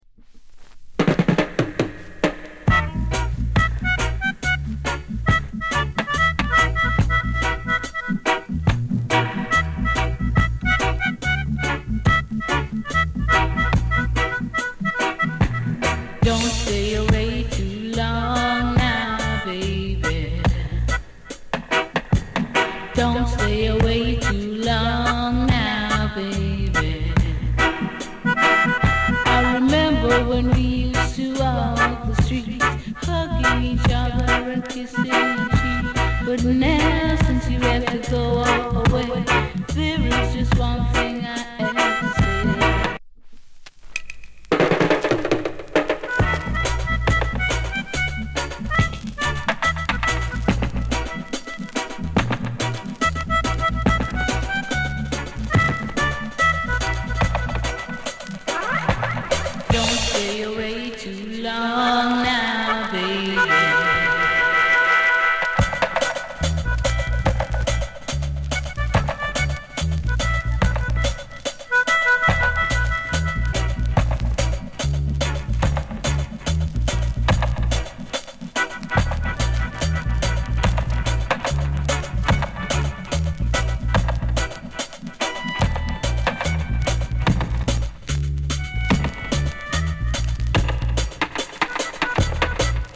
vg(heat on edge)(noise)